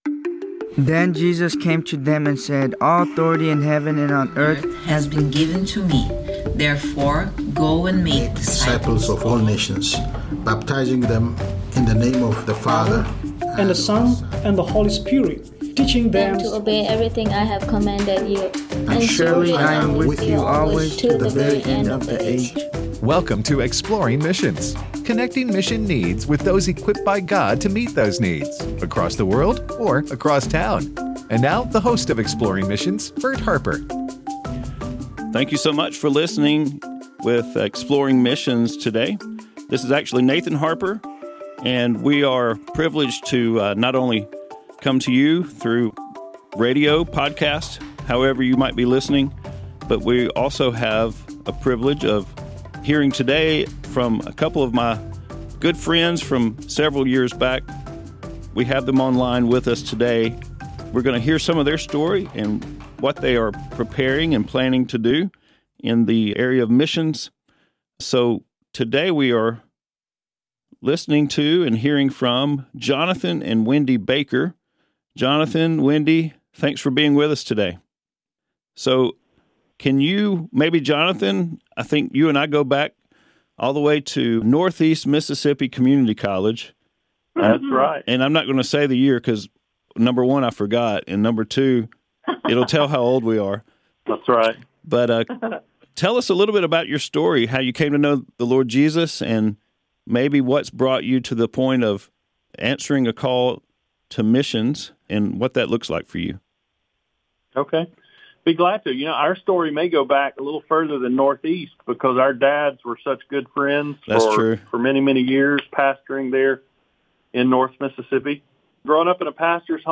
Answering the Call to Missions: A Conversation